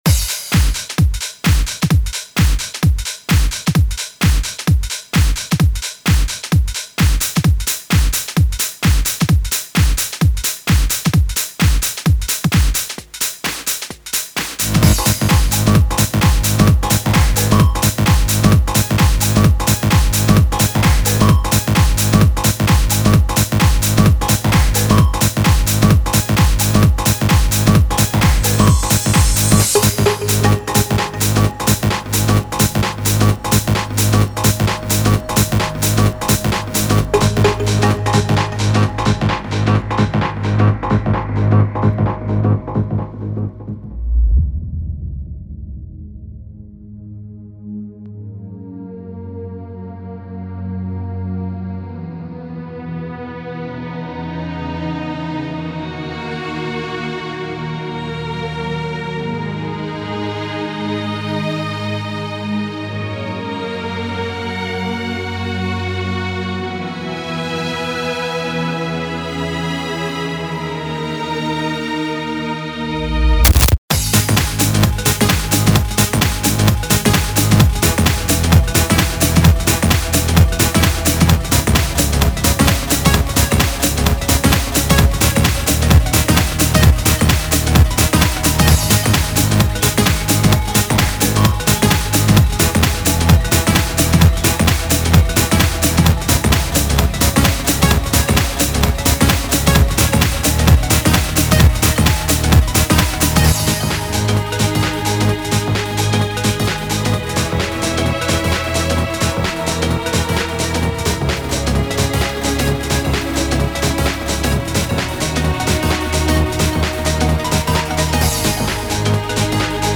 Style : HOUSE